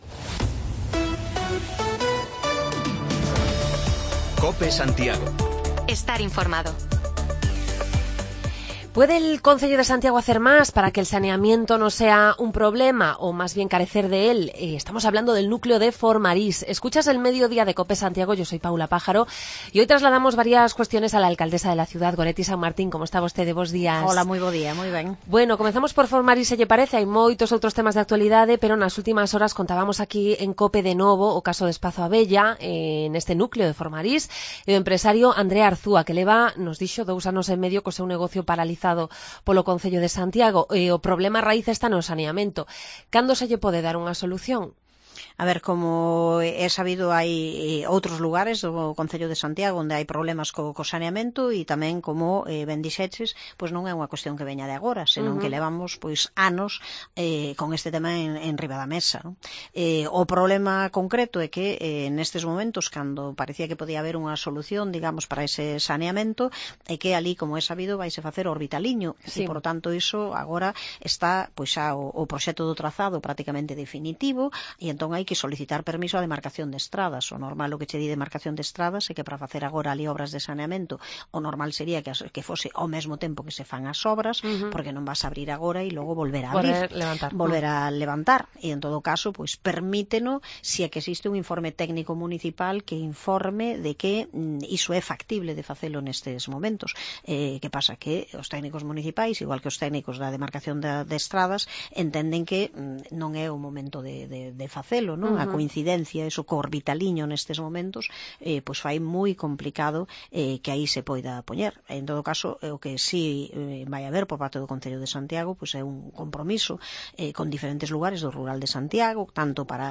Entrevistamos hoy en Mediodía Cope a la alcaldesa de Santiago, Goretti Sanmartín, para tratar con ella los asuntos más destacados de la crónica local, como los problemas de aparcamiento en el Hospital Clínico y las conversaciones con la Xunta sobre este tema o la falta de saneamiento en Formarís, que mantiene precintado un establecimiento comercial desde hace más de dos años